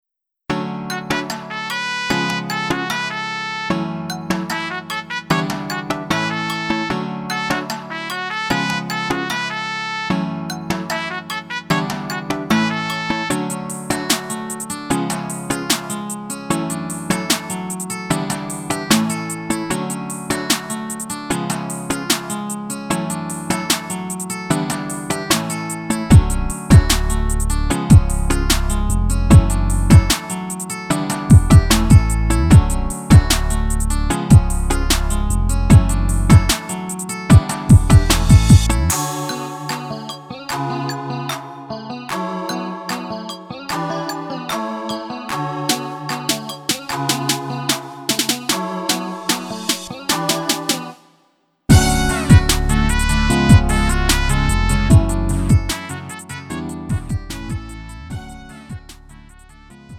음정 -1키 2:43
장르 가요 구분 Lite MR
Lite MR은 저렴한 가격에 간단한 연습이나 취미용으로 활용할 수 있는 가벼운 반주입니다.